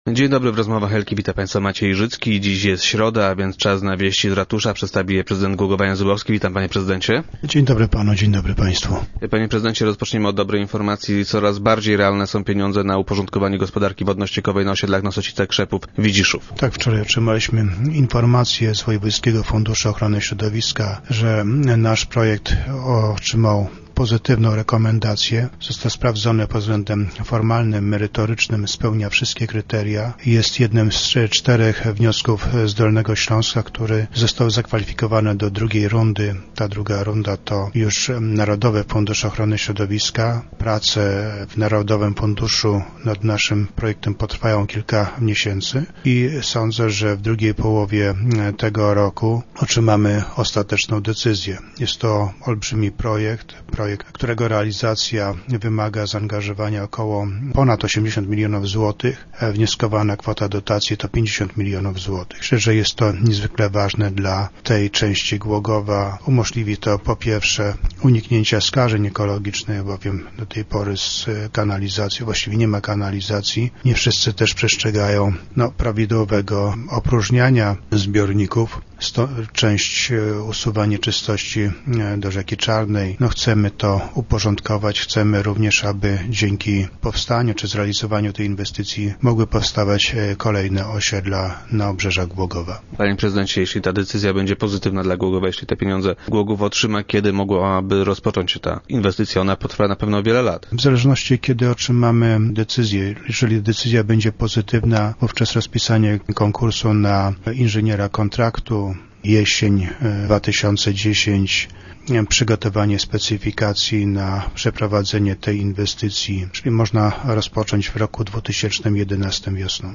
Podczas dzisiejszych Rozmów Elki, prezydent Jan Zubowski odpowiedział na krytykę jego rządów przez Obywatelskie Porozumienie Samorządowe. Jego zdaniem działacze OPS-u próbują wprowadzić w błąd mieszkańców miasta.